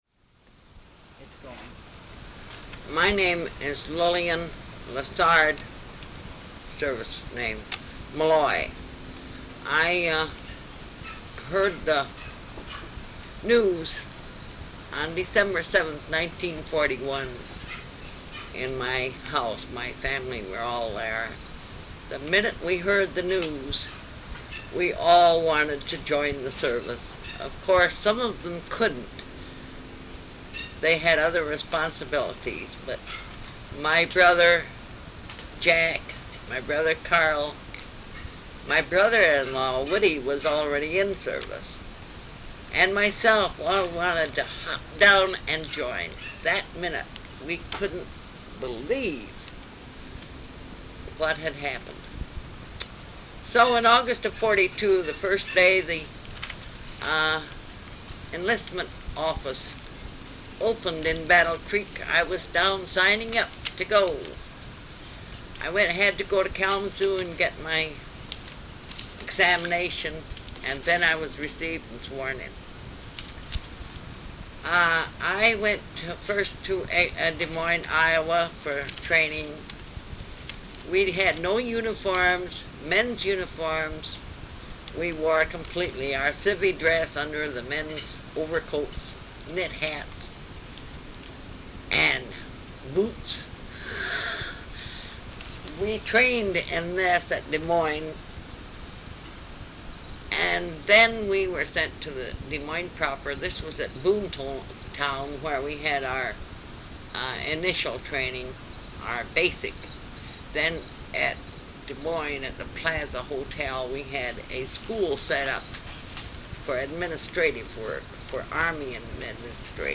Women's Overseas Service League Oral History Project